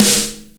Tuned snare samples Free sound effects and audio clips
• Snappy Snare Sample G# Key 41.wav
Royality free snare drum tuned to the G# note. Loudest frequency: 4953Hz
snappy-snare-sample-g-sharp-key-41-QuS.wav